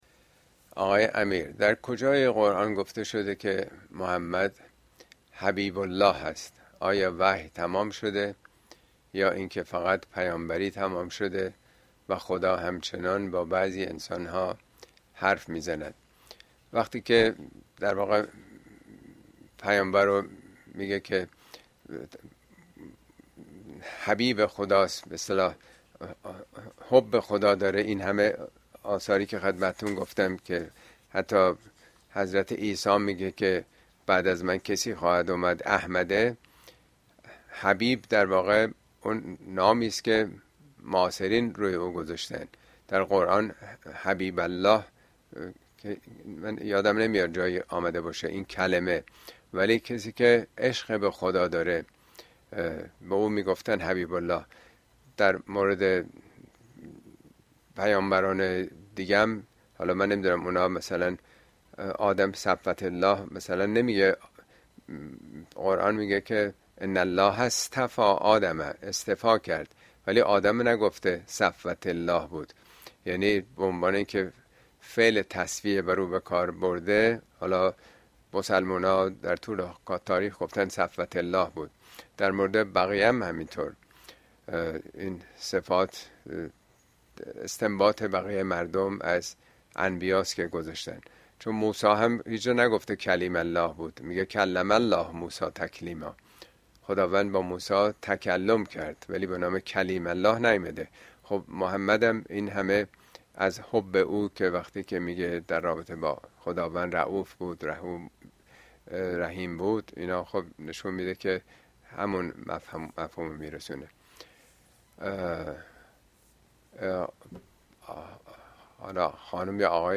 Speech on In the Eulogy of Mercy for the Worlds
پيامبر و امامان در رثای رحمة للعالمین! اين سخنرانى به مناسبت رحلت پیامبر مکرم در ۲۸ اگوست ۲۰۲۴ ايراد گرديده است توصيه ميشود براىاستماع سخنرانى از گزينه STREAM استفاده كنيد.